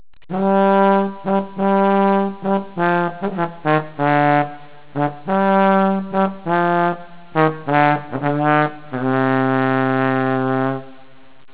Variously spelt "sackbut" or "sagbut", it is basically a slide trumpet which allows the player to alter the length of the sound tube as he/she plays. As the sound tube is extended, the notes get lower.
Sackbutt Sound Clips